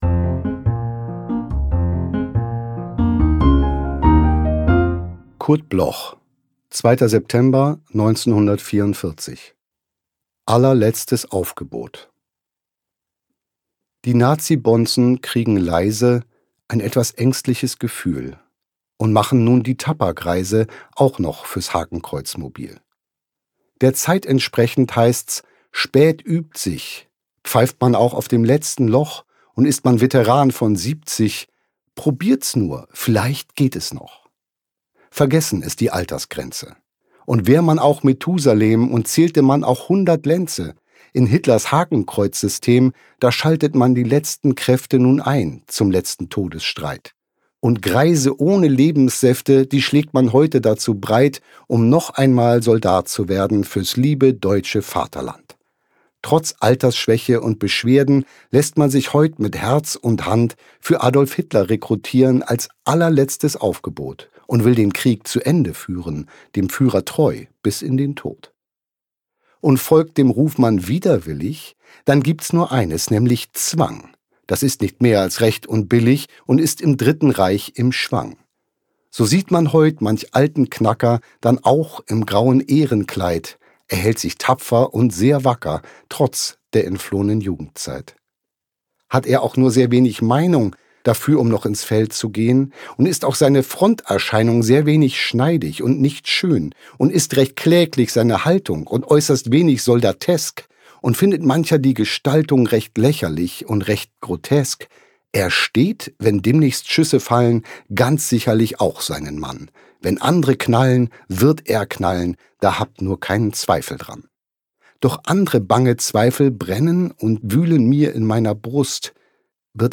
B.Maedel_ALLERLETZTES-ANGEBOT_mit-Musik.m4a